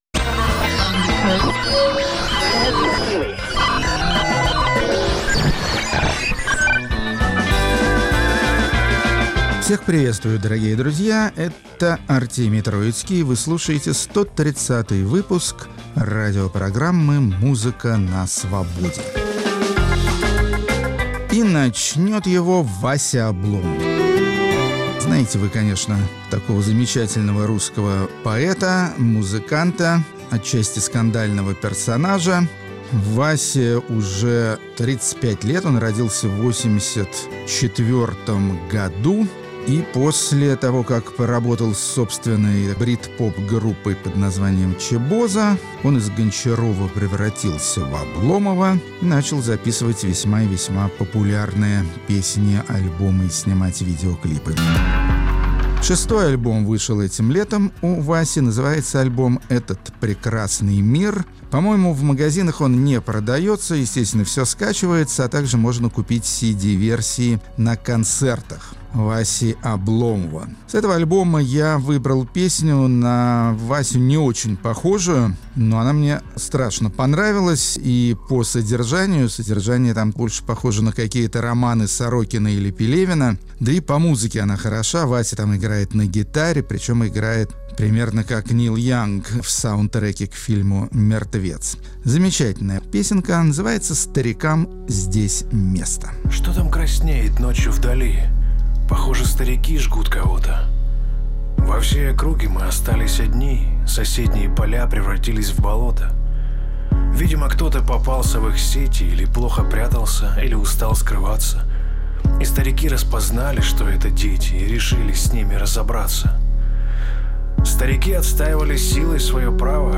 Музыка на Свободе. 29 сентября, 2019 Исполнители разнообразных мелодий из южноитальянской области Апулия. Рок-критик Артемий Троицкий убедился в том, что для счастливой жизни необязательны попса и рок, вполне достаточно яркой world music и вина primitivo.